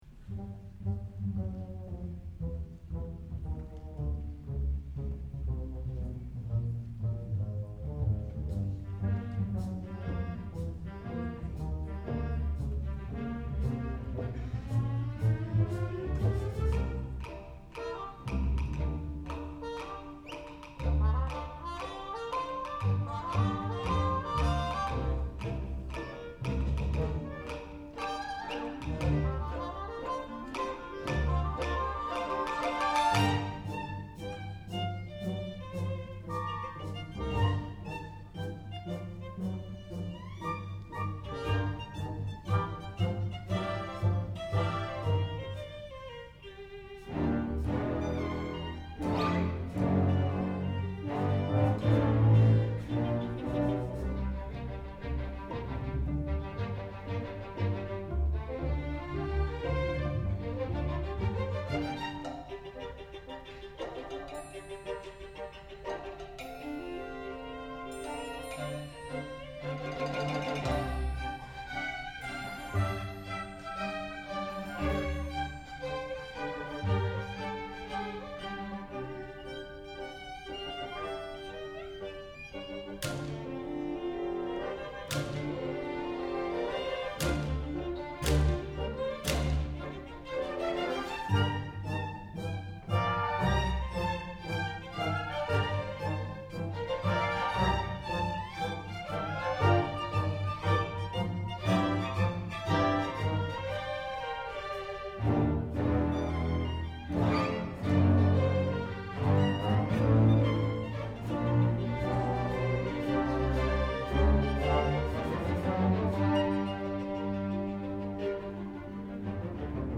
for Violin and Orchestra (1995)